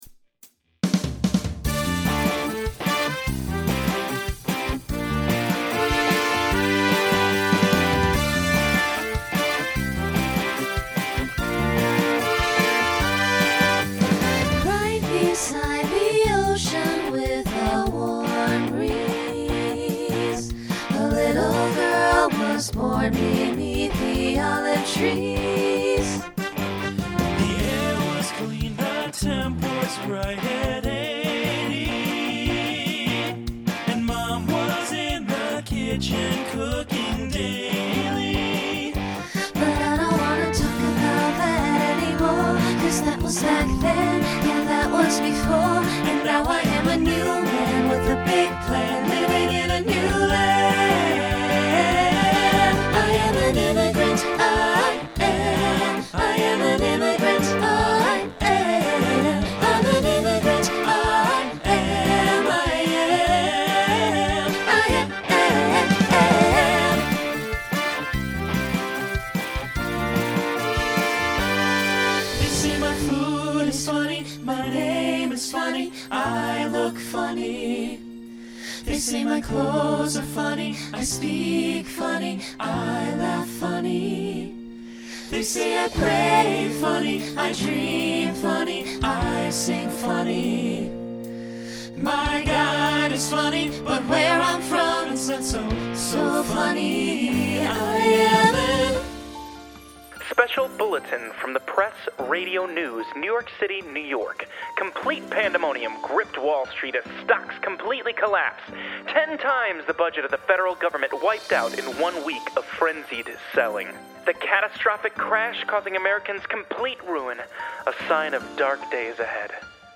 Genre Pop/Dance , Rock Instrumental combo
Story/Theme Voicing SATB